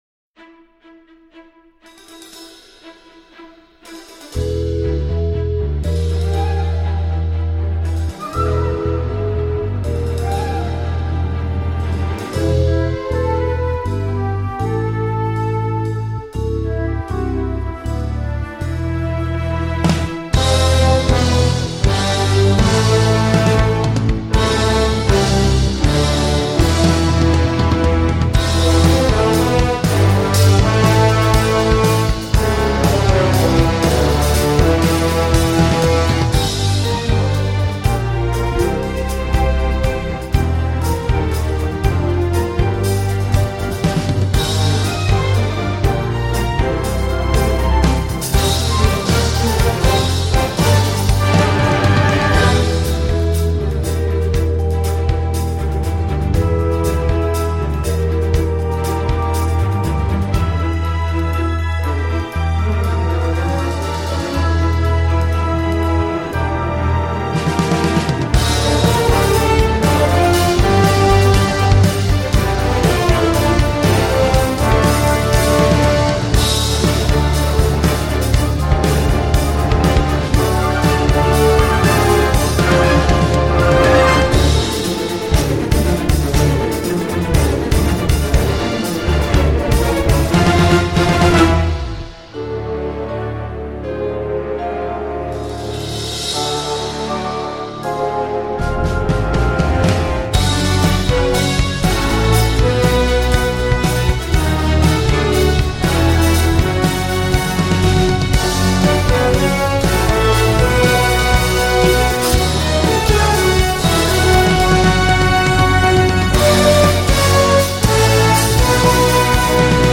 entre orchestre, sonorités vintage 70’s et hip-hop
j’aime bien ce genre de score rock-orchestral.
Un score finalement assez passe-partout et bruyant